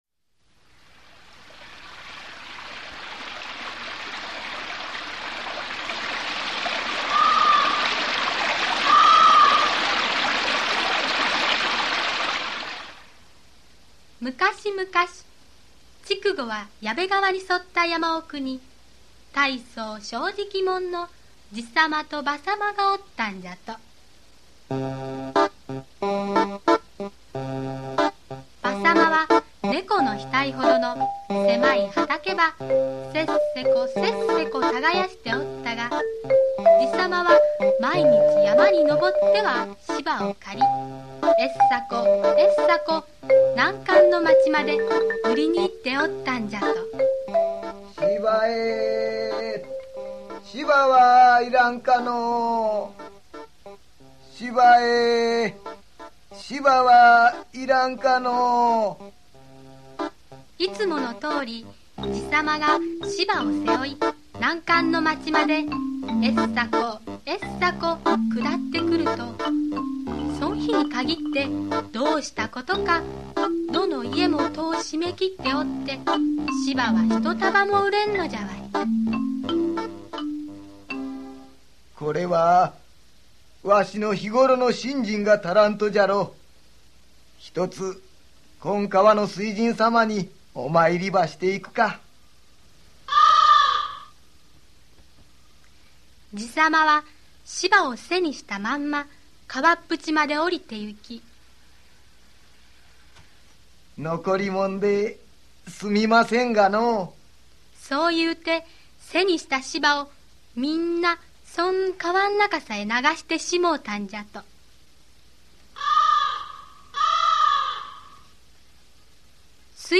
[オーディオブック] はなたれこぞうさま